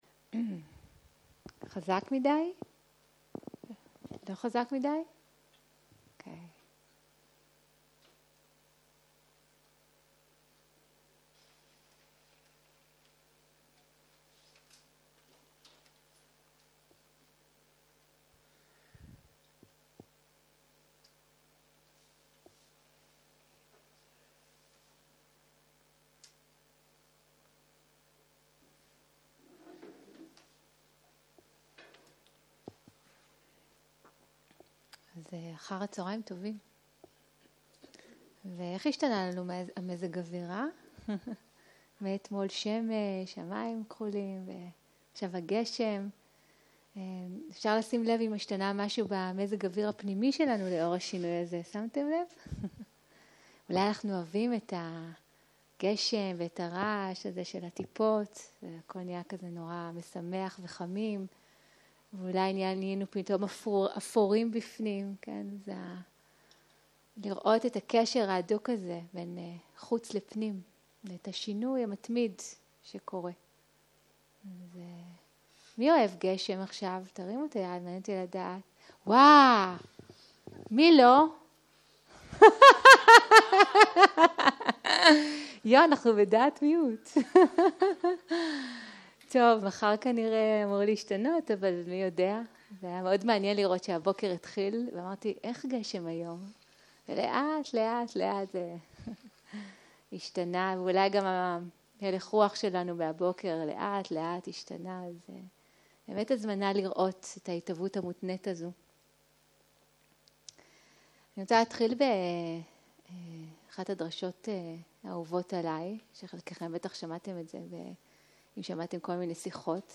Dharma talk language